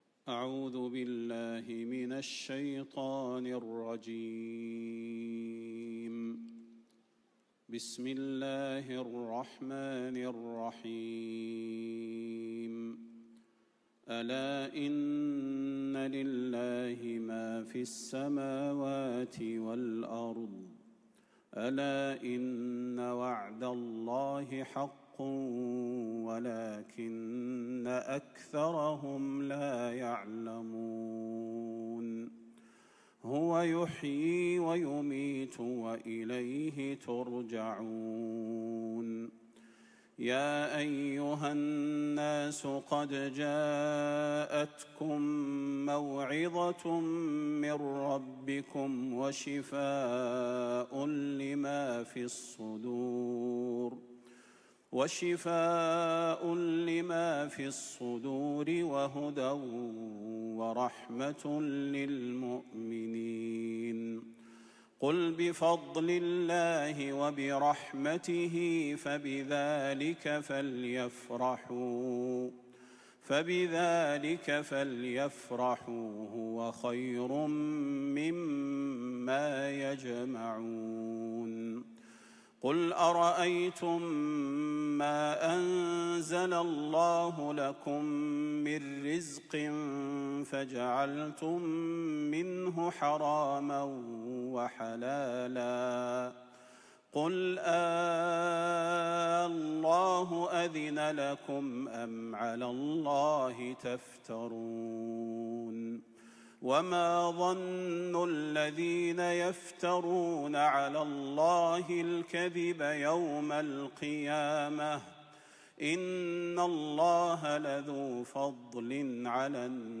تلاوة الشيخ صلاح البدير في الحفل الختامي لمسابقة القرآن الكريم في دولة ماليزيا > زيارة الشيخ صلاح البدير لـ دولة ماليزيا > تلاوات و جهود الشيخ صلاح البدير > المزيد - تلاوات الحرمين